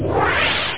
Amiga 8-bit Sampled Voice
PanelSwosh.mp3